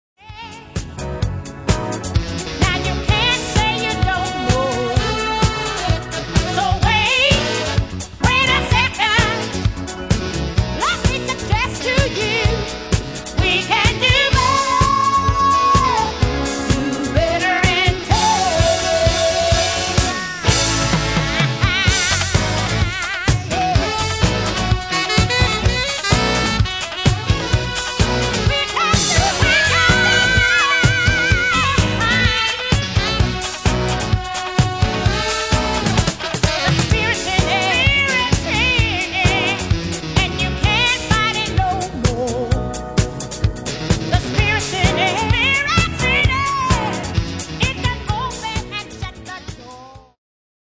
Genere:   Soul | Disco | Funky